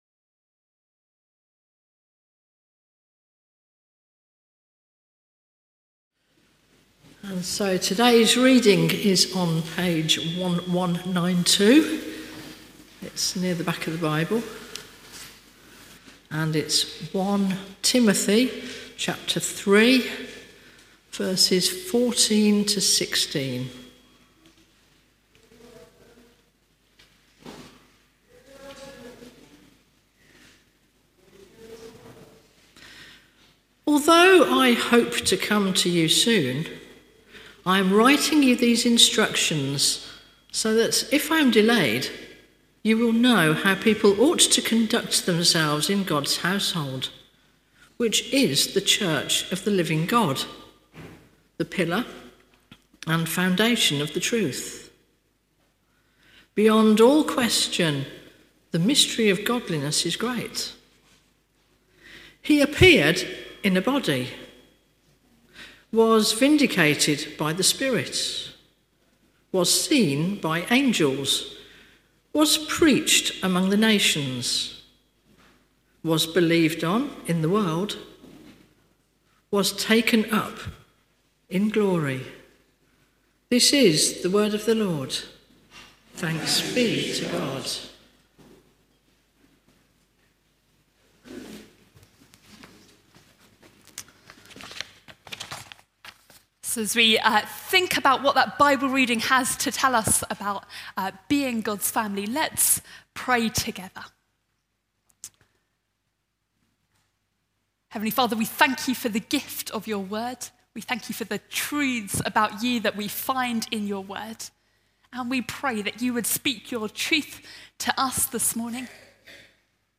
At our monthly family service this morning